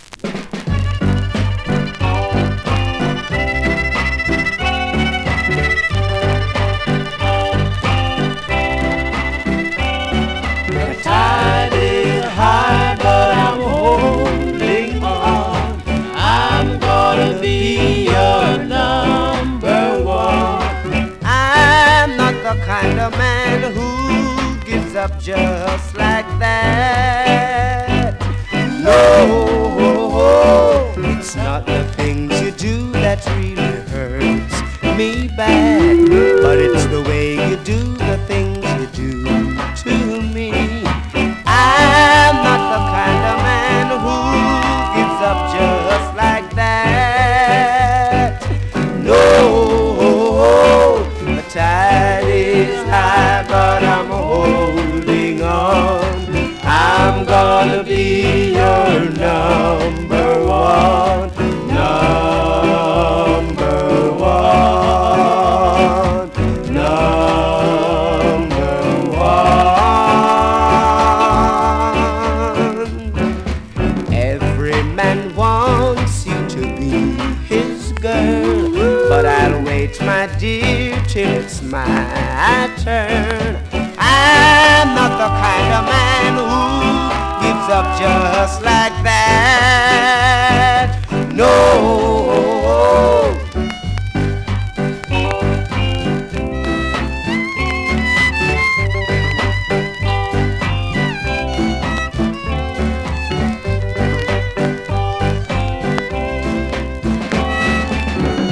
(少し声割れ有)
スリキズ、ノイズ比較的少なめで